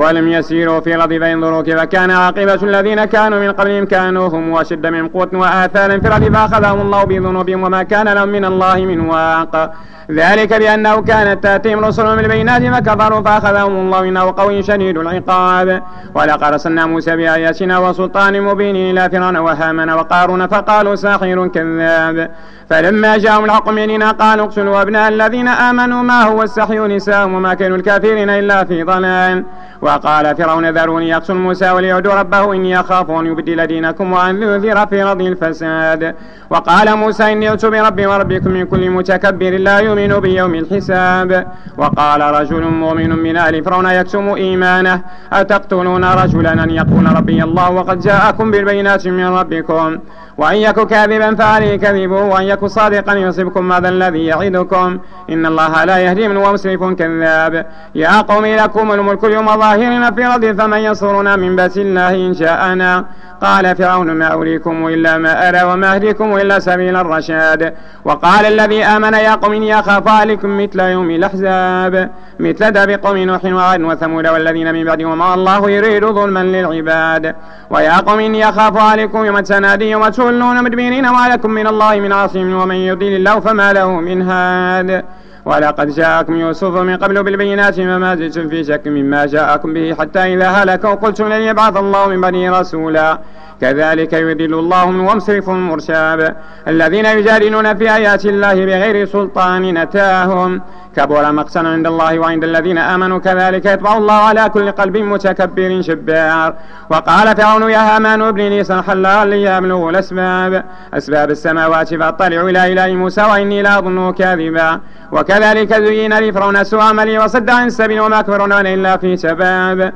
صلاة التراويح رمضان 1431/2010 بمسجد ابي بكر الصديق ف الزوى